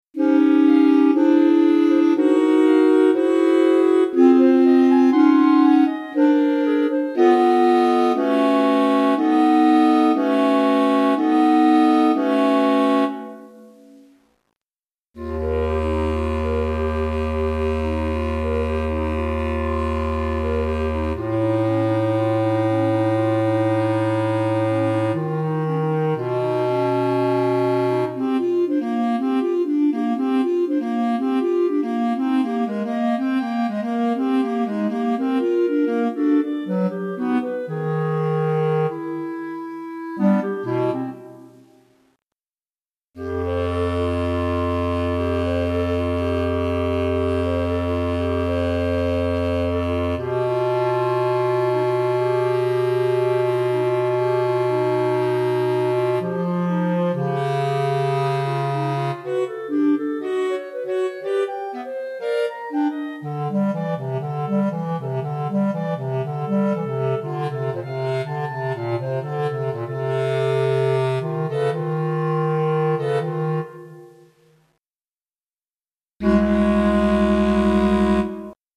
4 Clarinettes